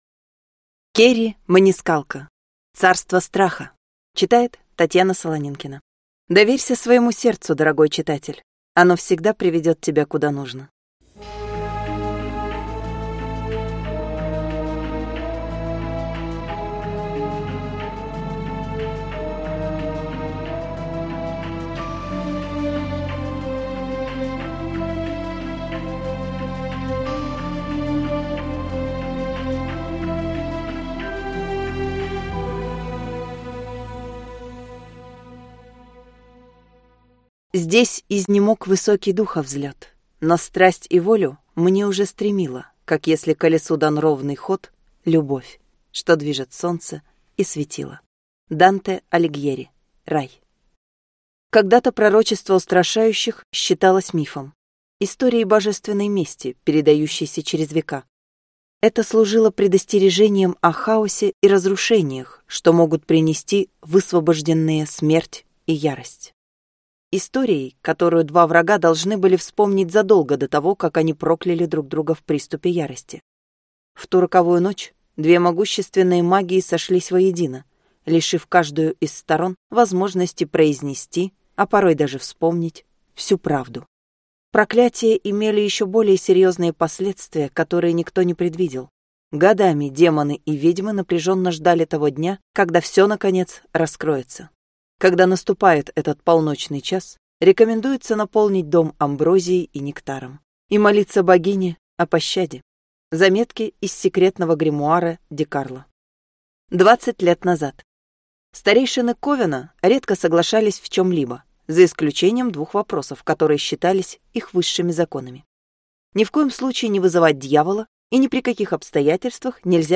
Аудиокнига Царство Страха | Библиотека аудиокниг